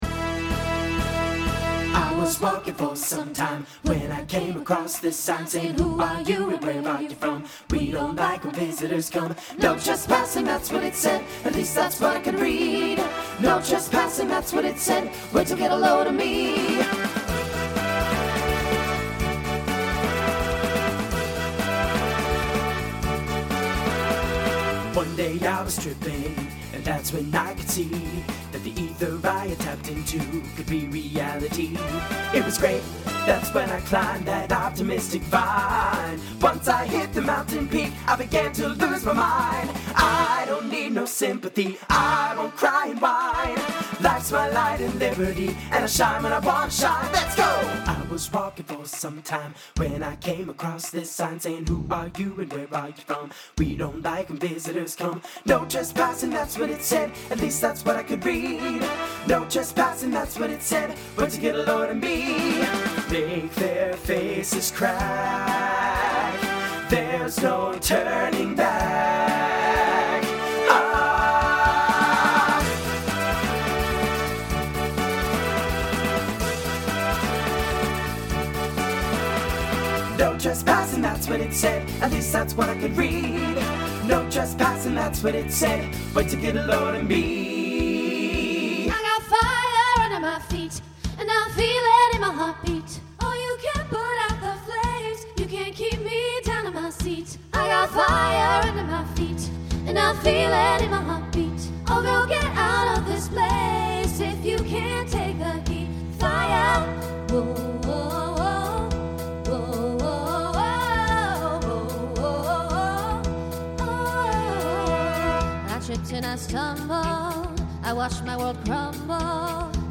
TTB/SSA
Voicing Mixed Instrumental combo Genre Pop/Dance , Rock